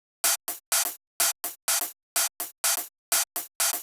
32 Hihat.wav